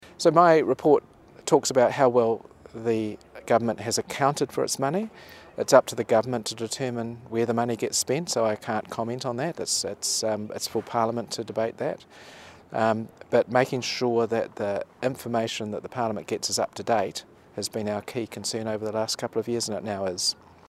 John Ryan-NZ Auditor General